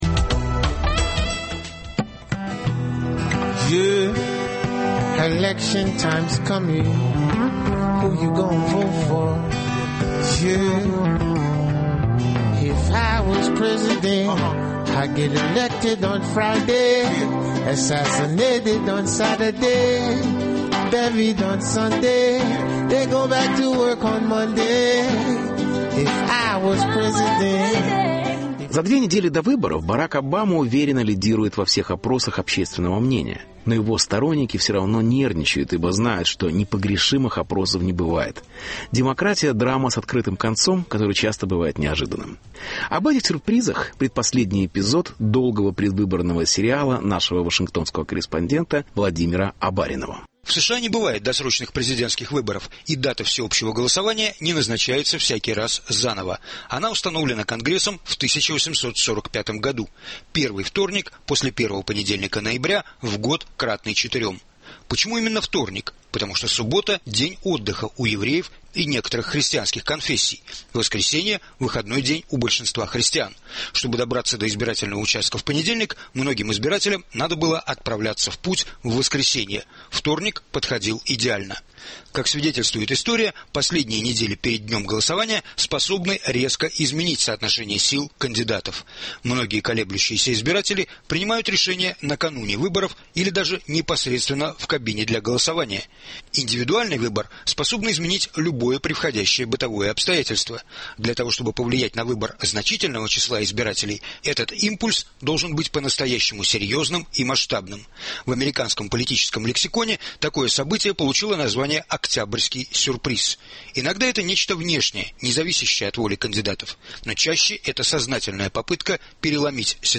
Репортаж. За две недели до президентских выборов: